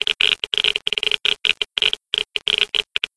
geiger_level_1.ogg